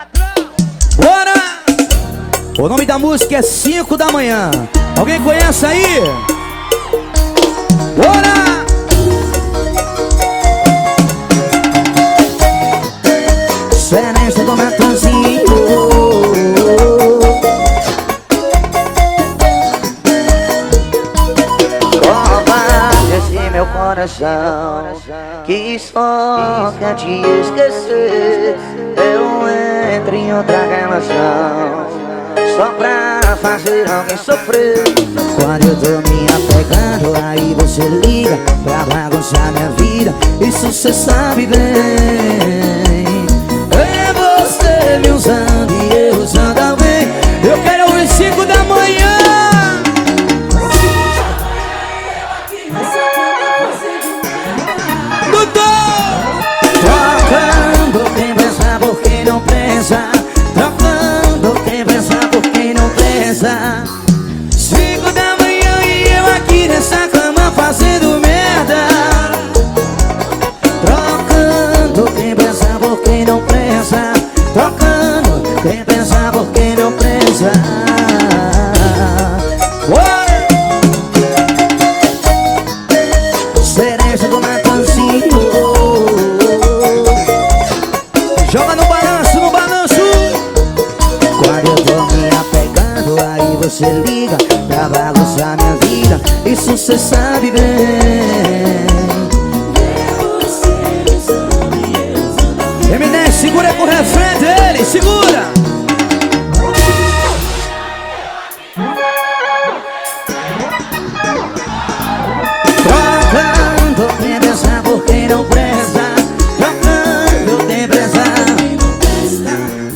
2025-01-29 10:34:13 Gênero: Forró Views